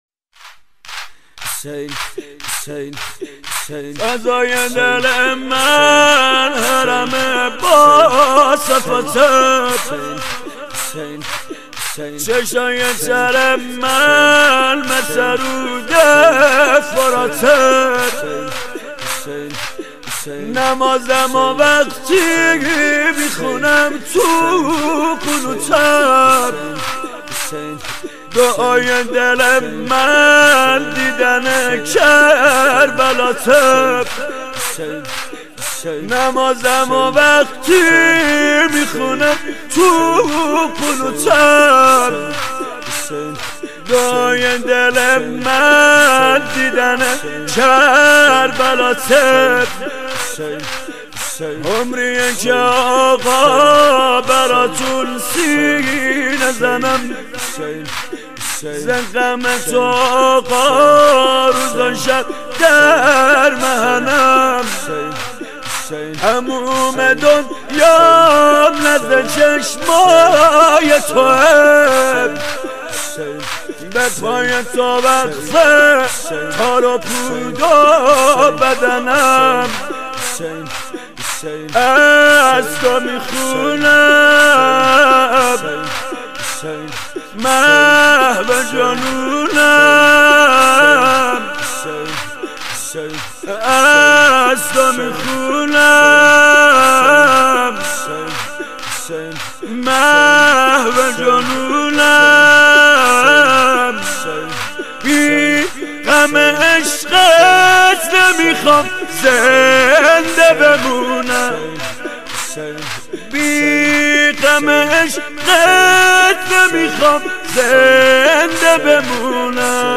هیئت نوجوانان خیمة الانتظار زنجان